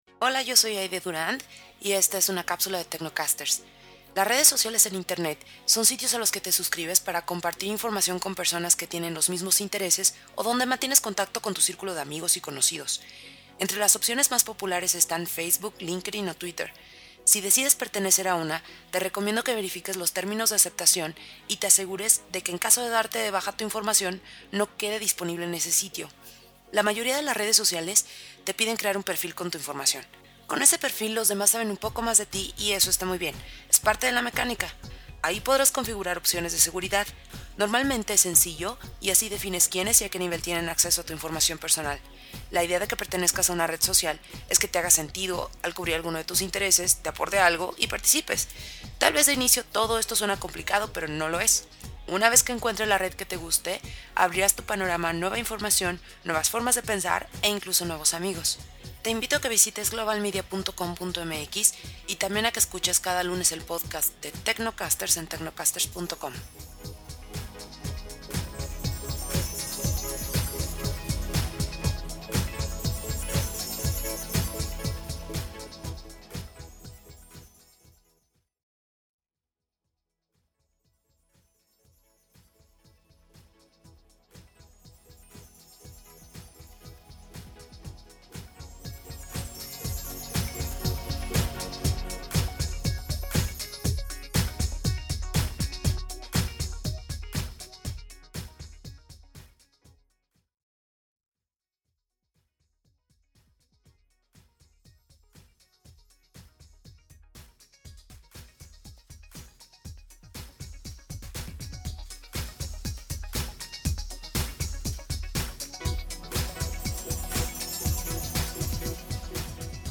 Capsula para transmision en Radio